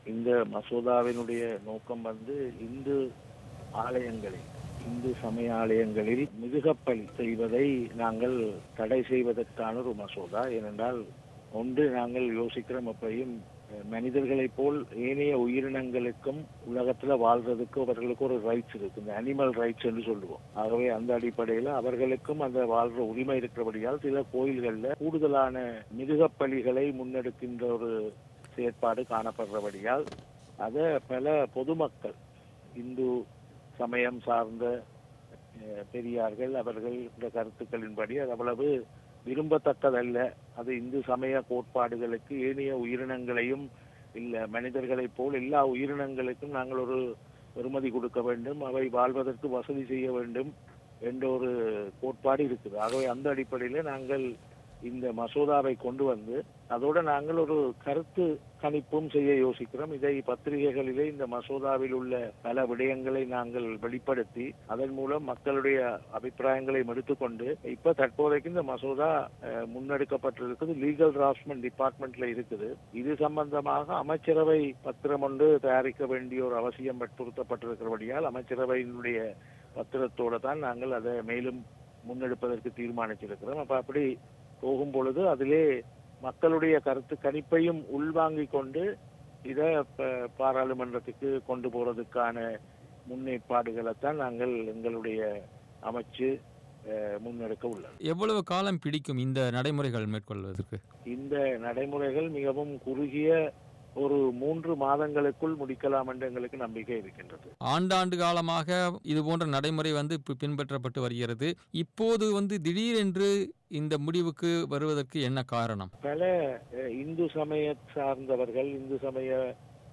அமைச்சக செயலர் பேட்டி
அவரது பேட்டியின் முழுவடிவத்தை நேயர்கள் கேட்கலாம்.